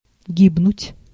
Ääntäminen
Synonyymit умирать погибать Ääntäminen Tuntematon aksentti: IPA: /ˈɡʲibnʊtʲ/ Haettu sana löytyi näillä lähdekielillä: venäjä Käännöksiä ei löytynyt valitulle kohdekielelle. Translitterointi: gibnut.